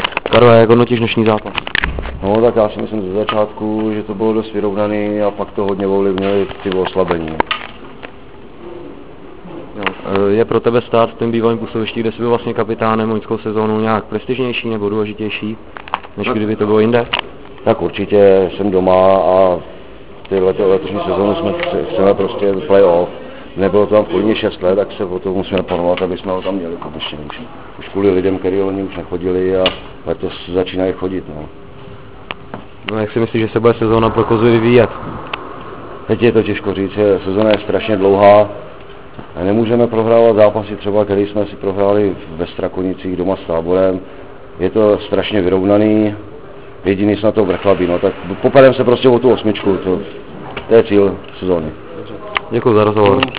Zvukový záznam ohlasu